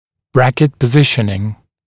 [‘brækɪt pə’zɪʃnɪŋ][‘брэкит пэ’зишнин]позиционирование брекета